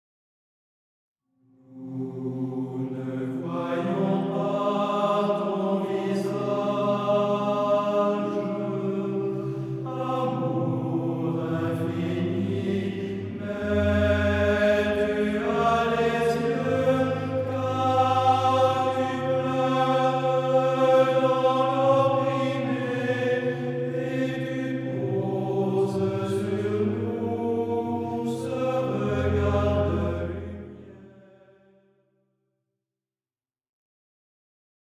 Hymnes & Tropaires
Format :MP3 256Kbps Stéréo